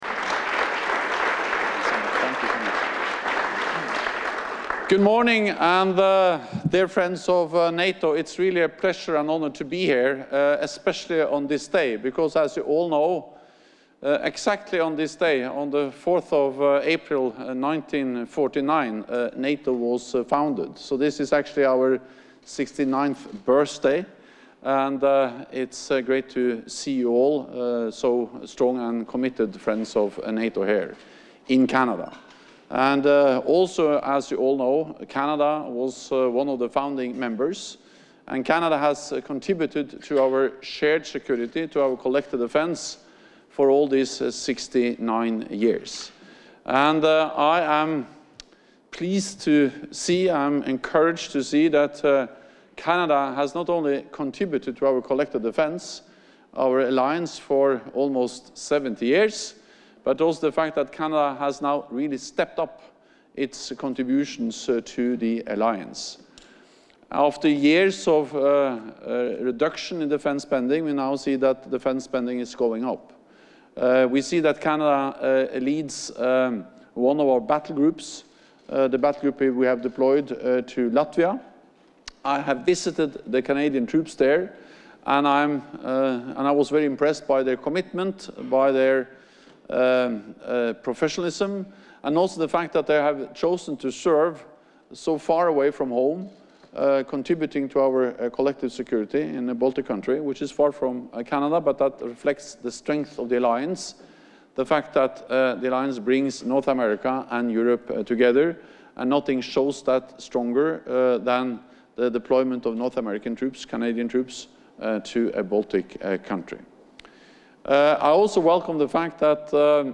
Joint press conference with NATO Secretary General Jens Stoltenberg and the Prime Minister of Canada, Justin Trudeau